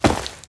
SFX_saltoSacos3.wav